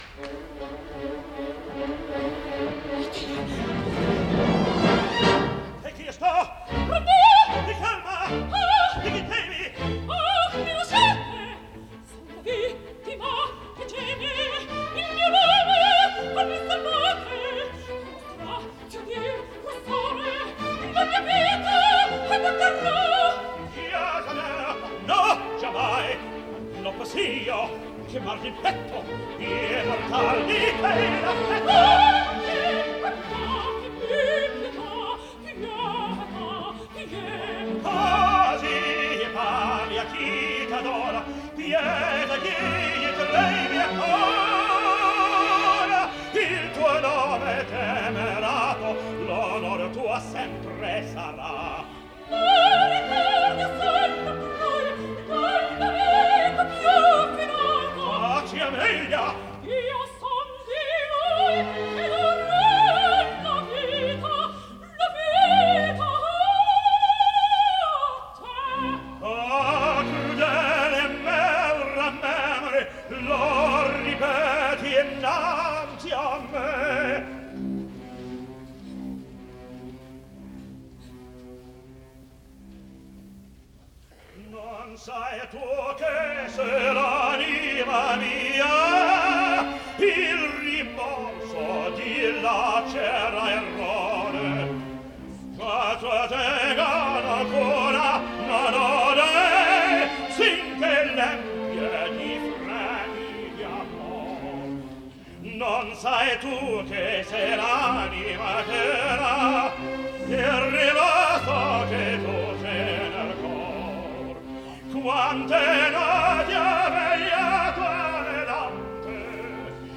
Запись 10 декабря 1955 года, Metropolitan Opera.